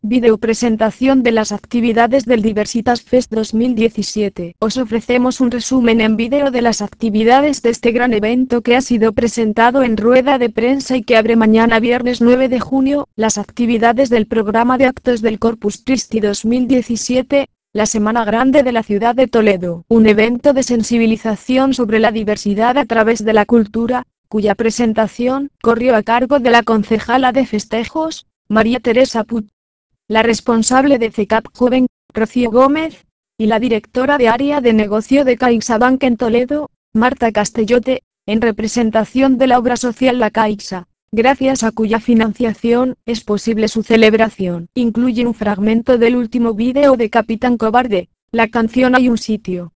Incluye un fragmento del último vídeo de "Capitán Cobarde", la canción 'Hay un sitio'.